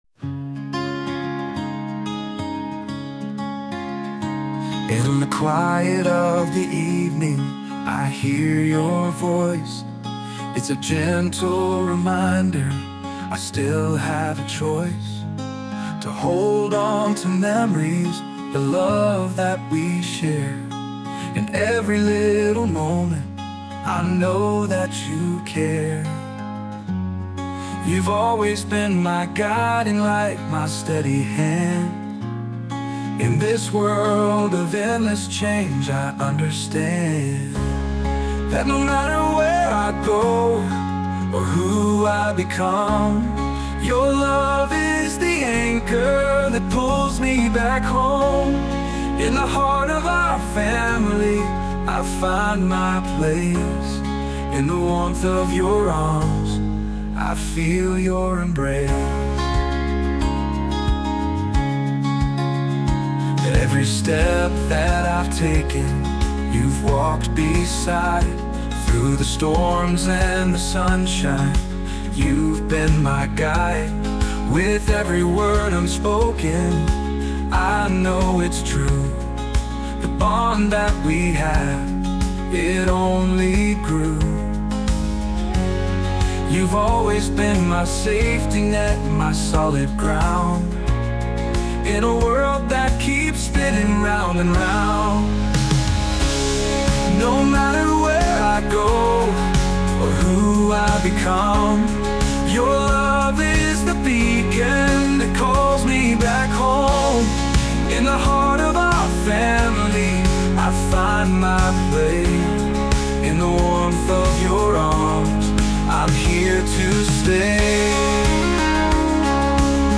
著作権フリーBGMです。
男性ボーカル（洋楽・英語）曲です。